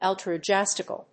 音節àl・tru・ís・ti・cal・ly 発音記号・読み方
/‐kəli(米国英語)/
音節al･tru･is･ti･cal･ly発音記号・読み方æ̀ltruɪ́stɪk(ə)li